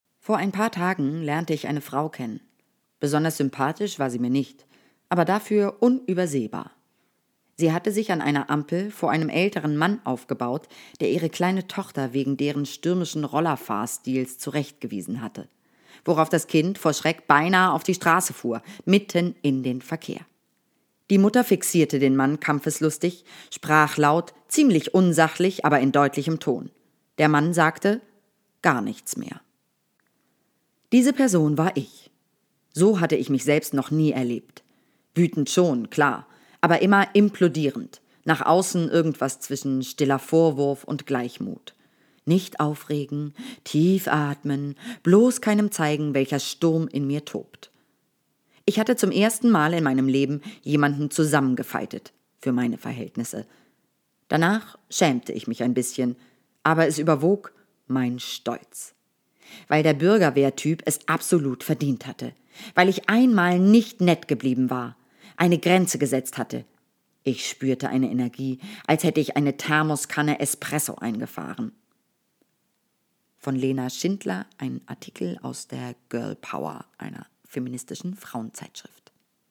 Comedystimmen Dialekt Berlinerisch